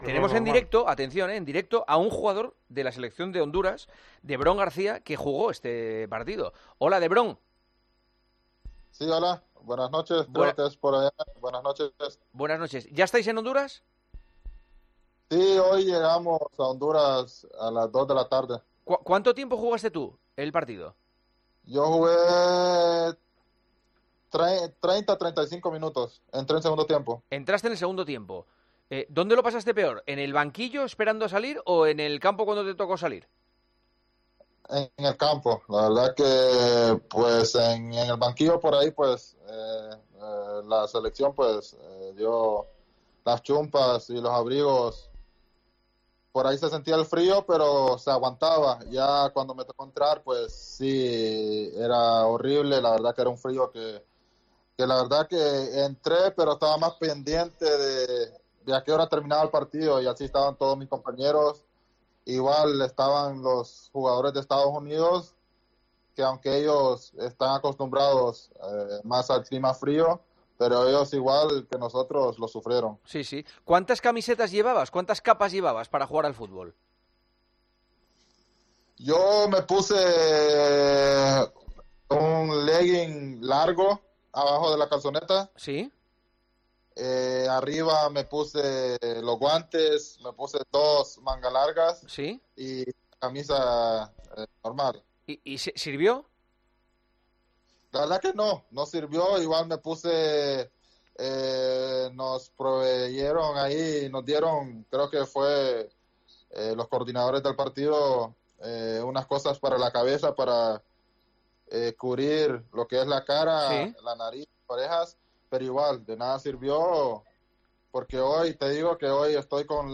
Hablamos con el futbolista hondureño de las malísimas condiciones en el partido ante EEUU, donde hubo temperaturas de hasta -18 grados.